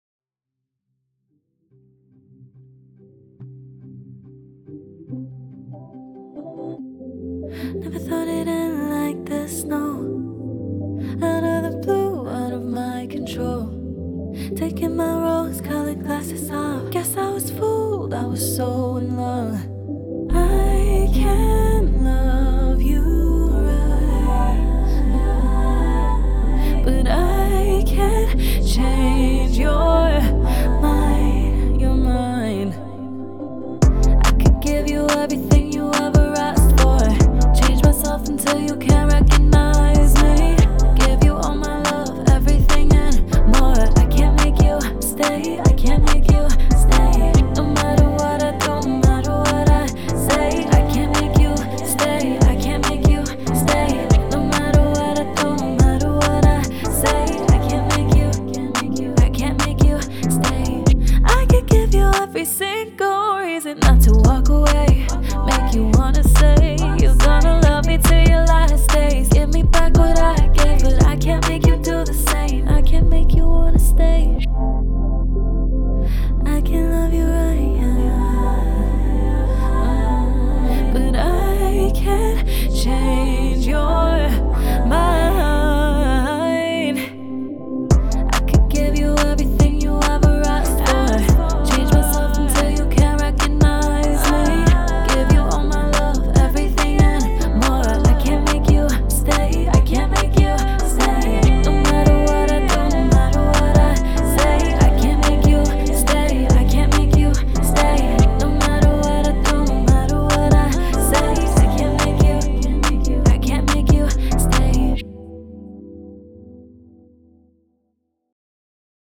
Pop
C Major